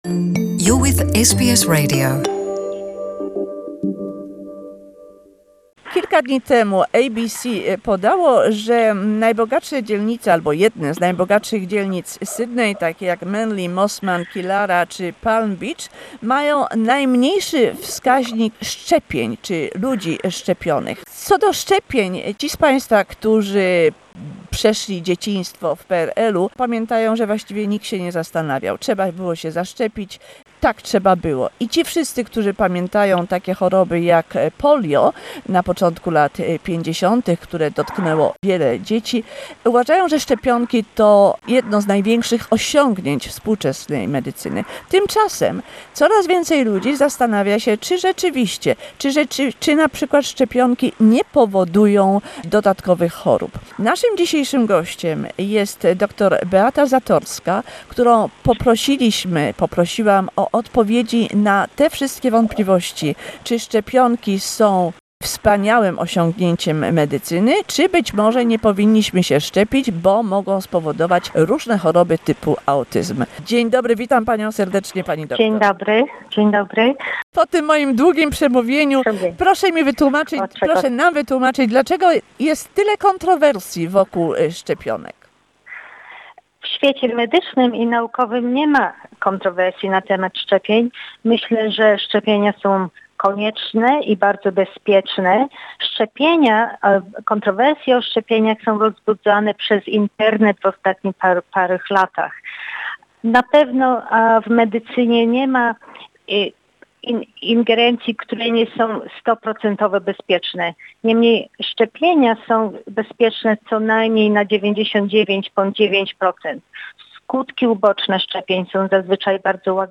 Vaccinations pros and cons. A conversation